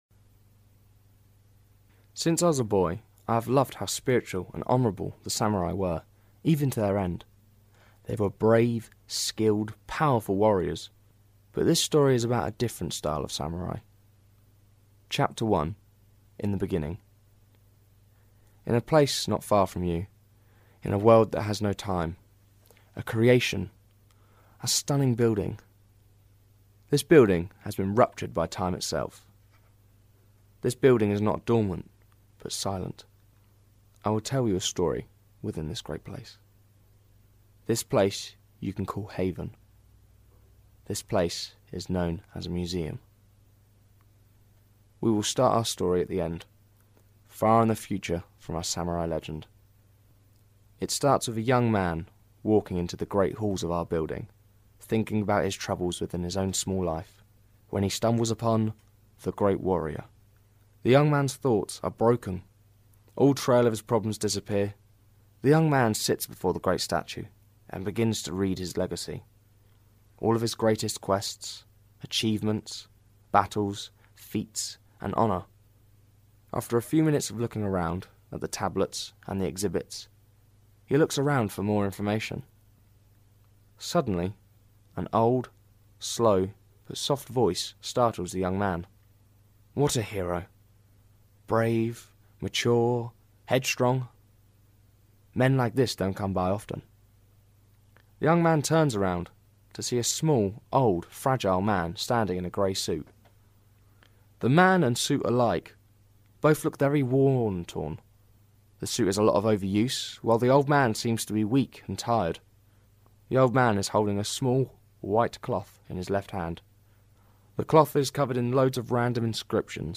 Samurai Bikers Audio Book